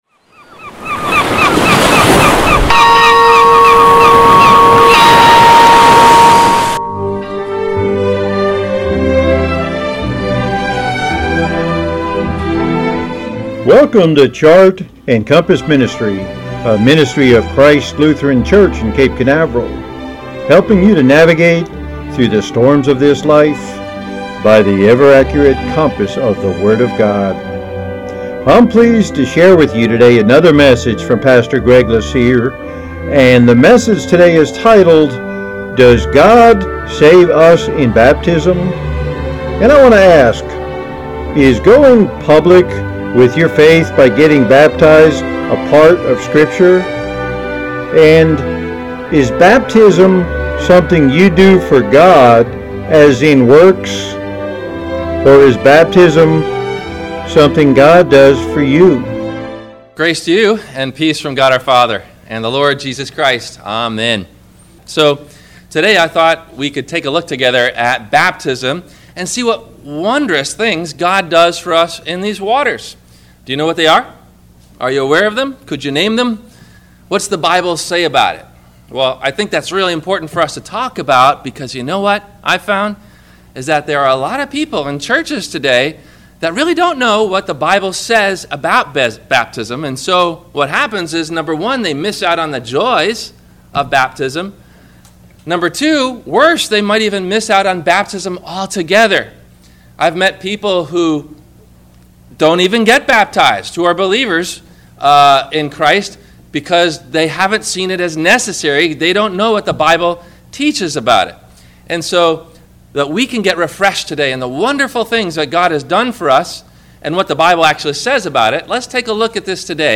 Questions asked before the Message: